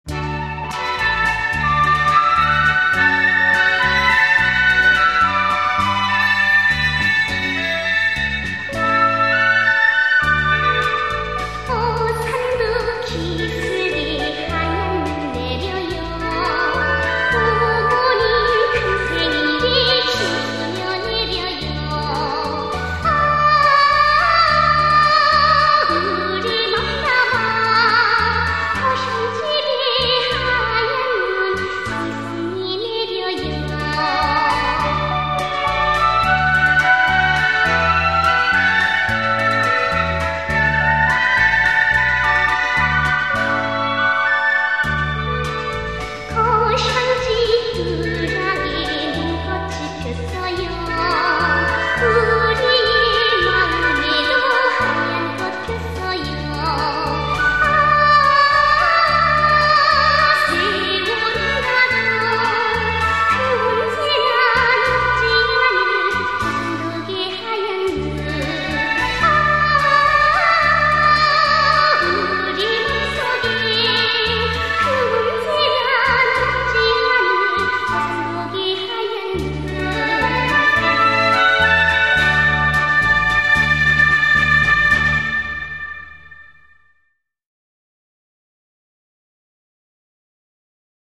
Korean Children's music